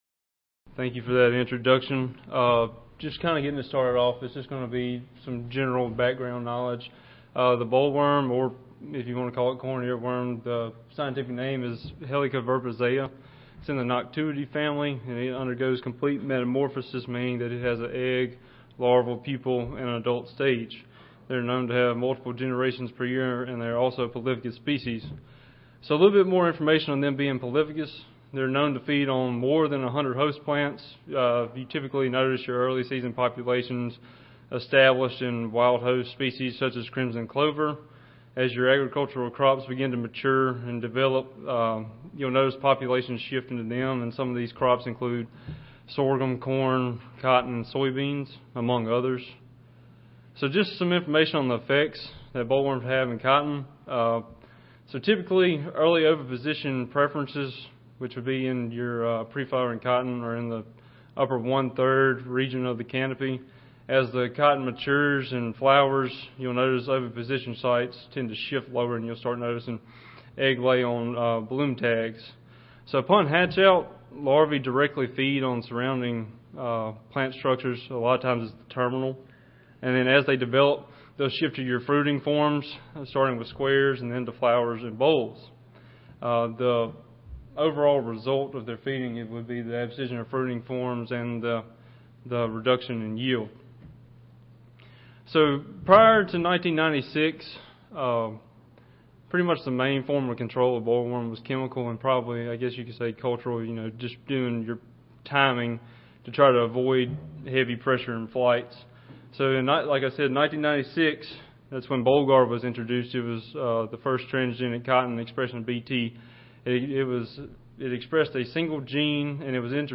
Recorded Presentation Field and laboratory experiments investigating bollworm survival and damage in Bt cotton will be discussed.